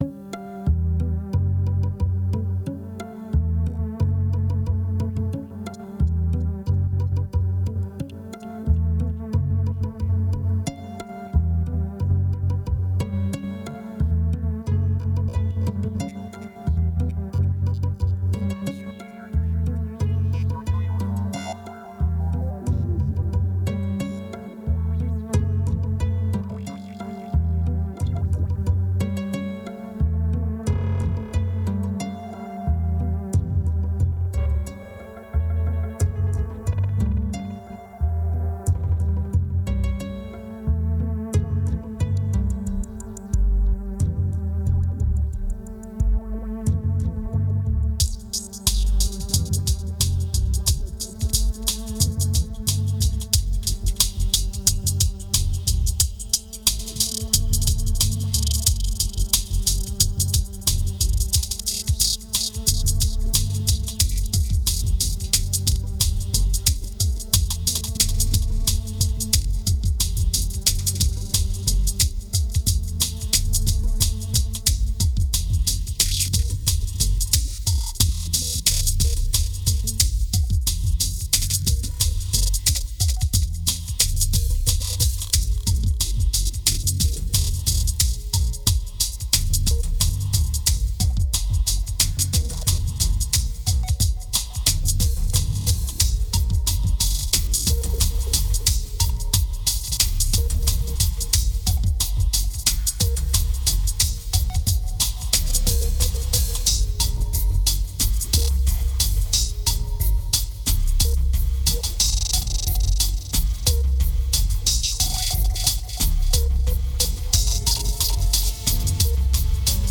2290📈 - -35%🤔 - 90BPM🔊 - 2010-01-09📅 - -307🌟